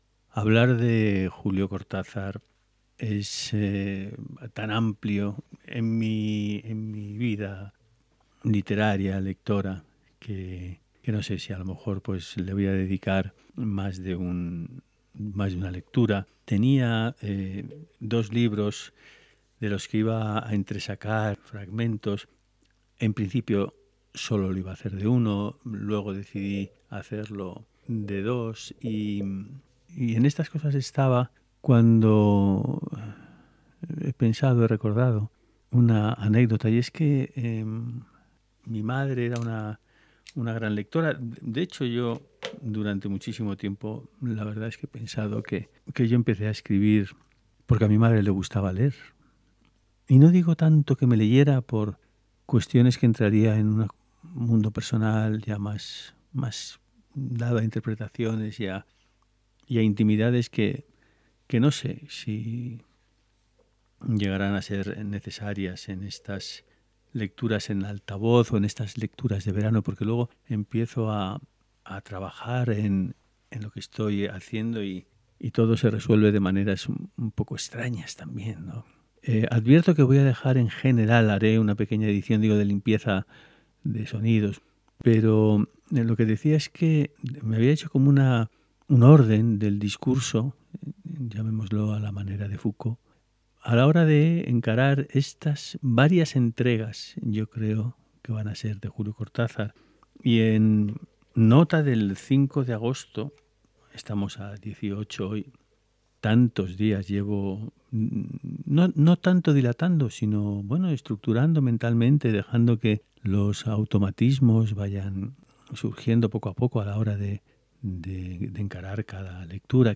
Lecturas en alta voz